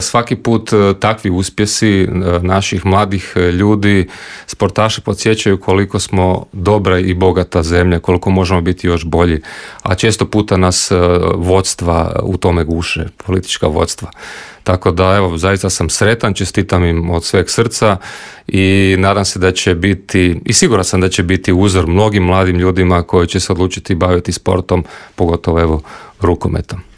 O svemu smo u Intervjuu Media servisa razgovarali sa saborskim zastupnikom SDP-a Mihaelom Zmajlovićem.